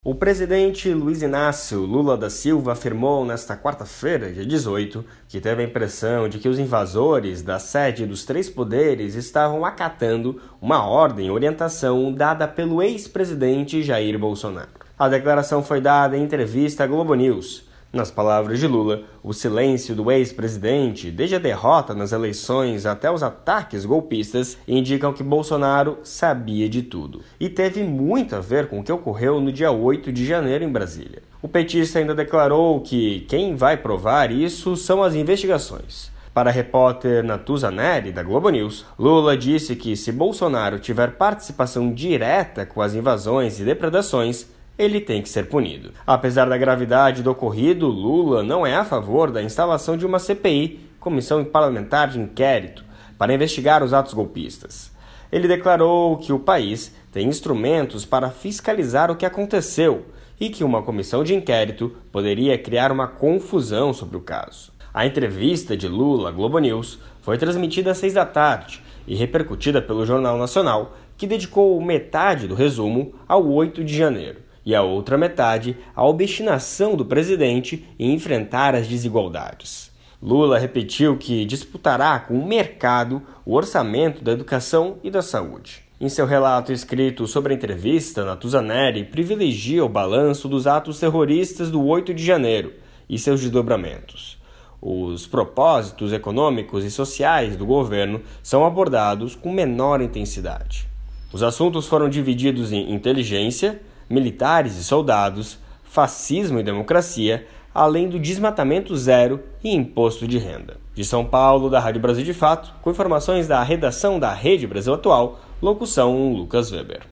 Lula em entrevista à GloboNews - Reprodução